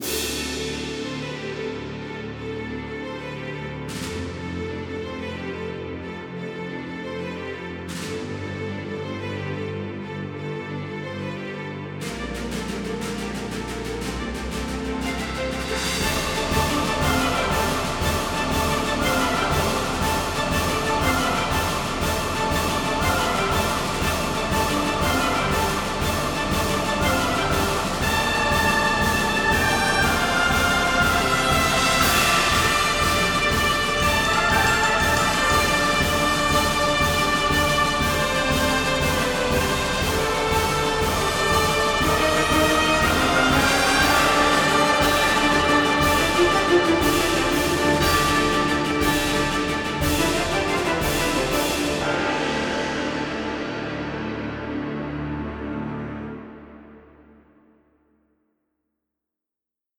中世の騎士団が戦場に進軍する様をイメージした、行進曲風の緊迫感のあるファンファーレ（※改良版）です。
・BPM：120
・メインの楽器：ストリングス、ブラス、クワイアなど ・テーマ：騎士、行進曲、緊迫感、壮大
エピックオーケストラ